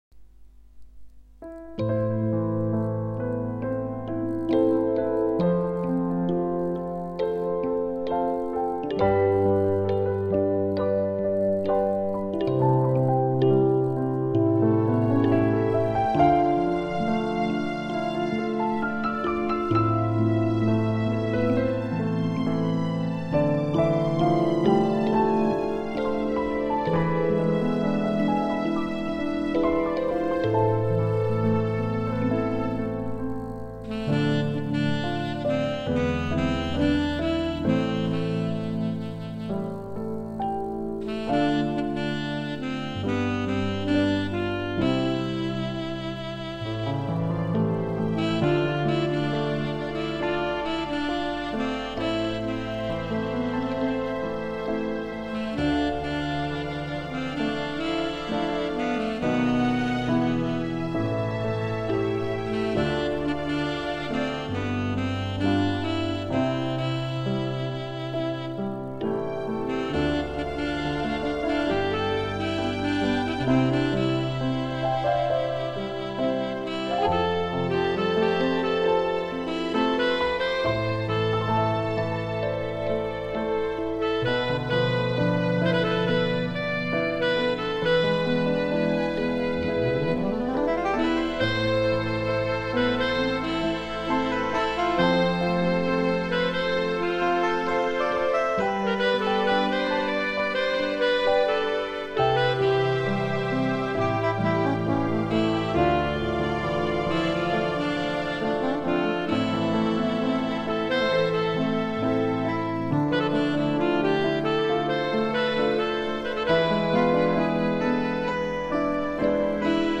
השיר הוקלט עם אורגן עם בערך 11 הקלטות אחת על גבי השנייה.
הסולו מדהים. בחרת את הצליל נכון. הרקע של הפסנתר גם.